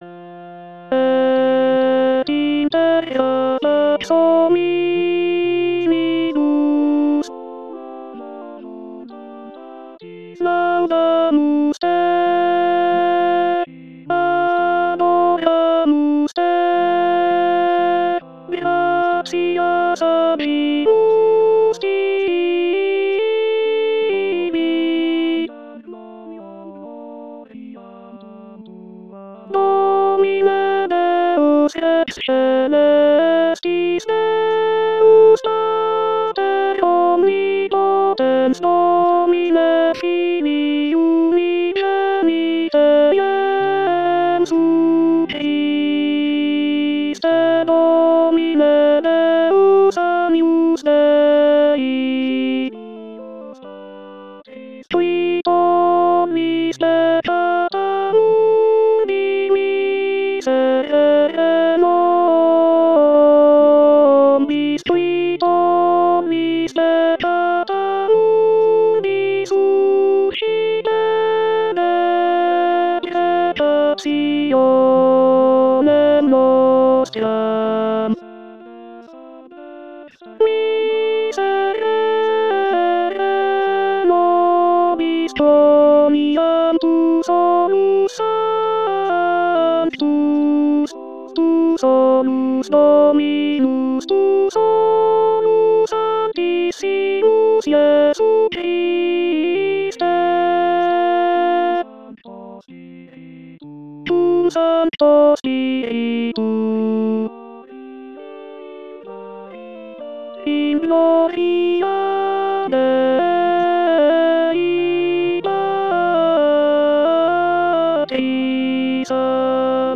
Gloria [guida audio Soprano]